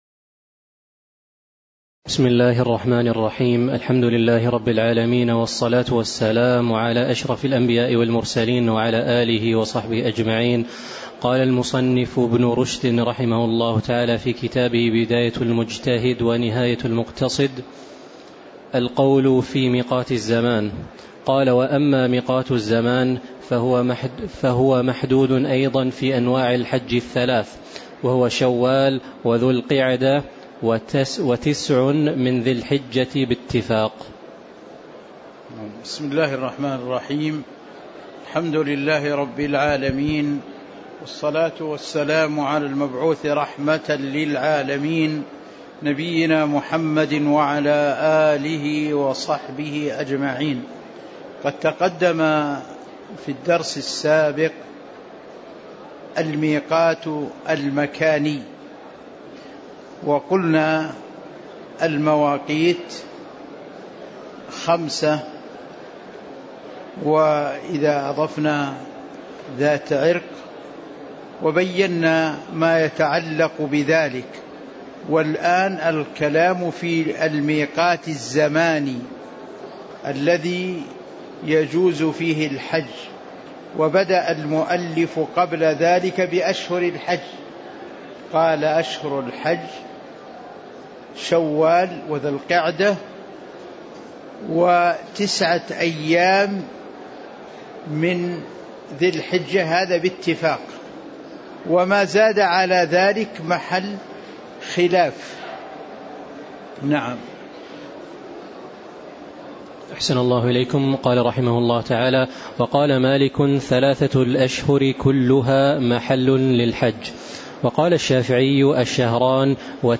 تاريخ النشر ١ ذو الحجة ١٤٤٤ هـ المكان: المسجد النبوي الشيخ